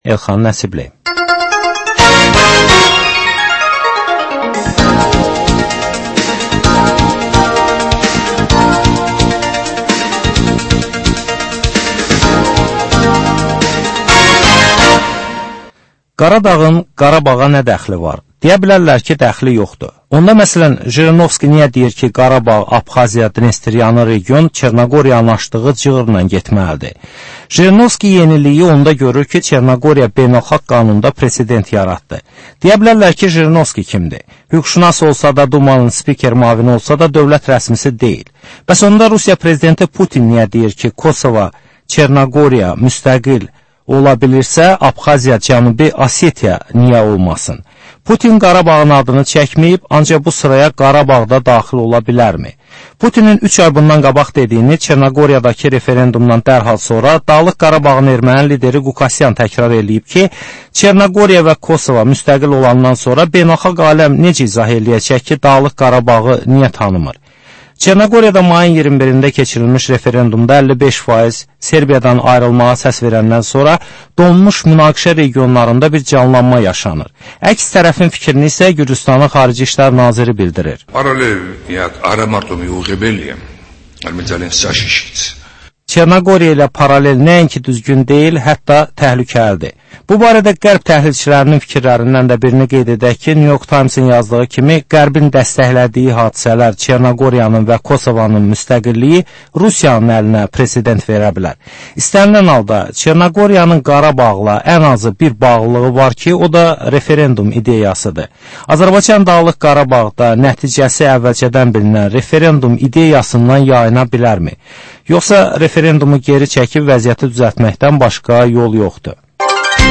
Aktual mövzu barədə canlı dəyirmi masa söhbəti.